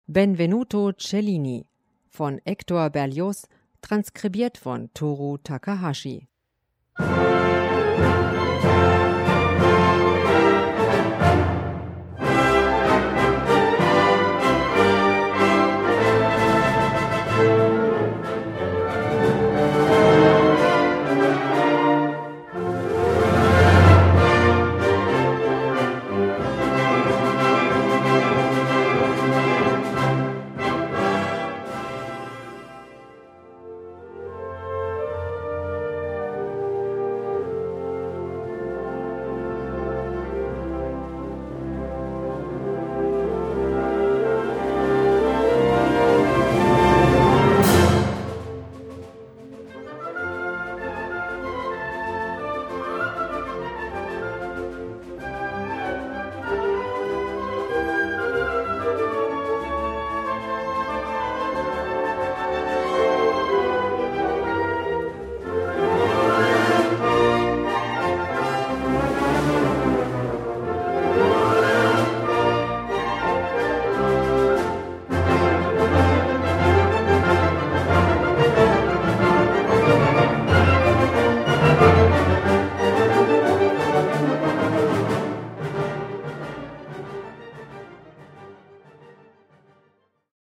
Besetzung: Blasorchester
mit Ausnahme der farbigen und lebhaften Ouvertüre
für Blasorchester